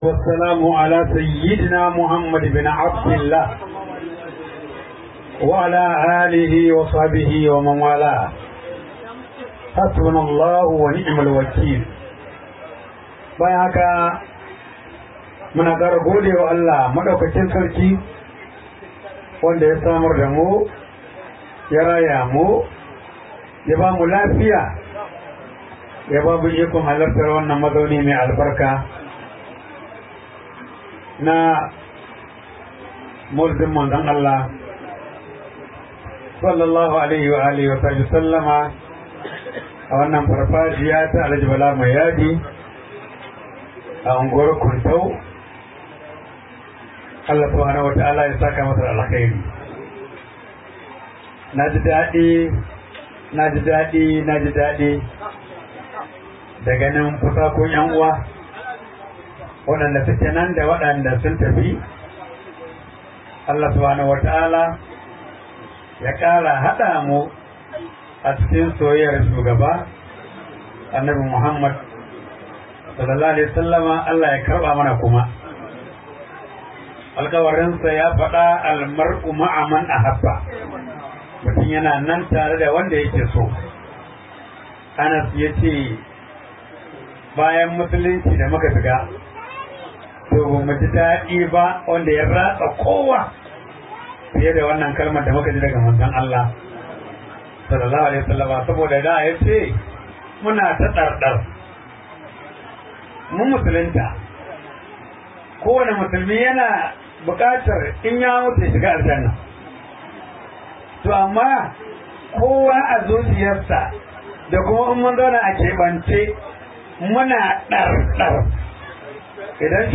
008 Maulidin Annabi SAW Daga Kuntau Kano.mp3